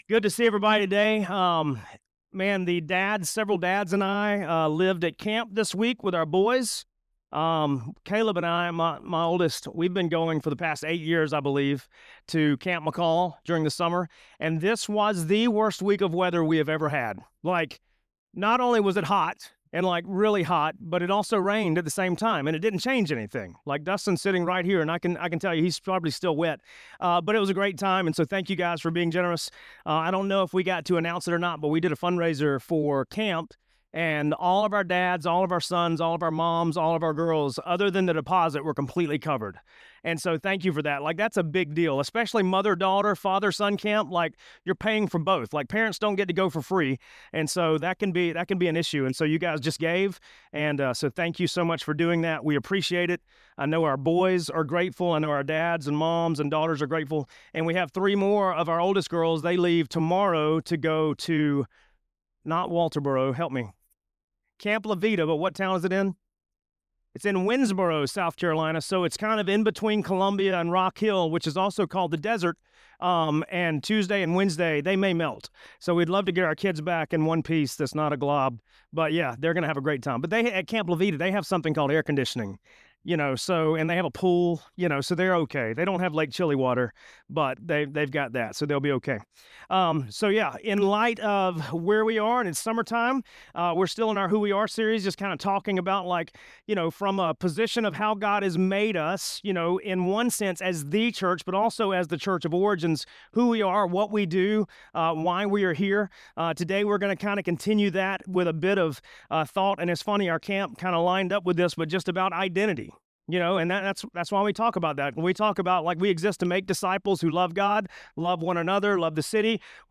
Sermons from our Sunday Worship Gatherings … continue reading 97 episodes # Religion # Encounter Jesus # Origins Greenville # Christianity # Kids And Families